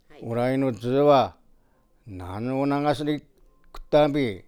Type: Single wh-question
Final intonation: Falling
WhP Intonation: Rising
Location: Showamura/昭和村
Sex: Male